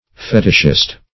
Meaning of fetishist. fetishist synonyms, pronunciation, spelling and more from Free Dictionary.
Fetishist \Fe"tish*ist\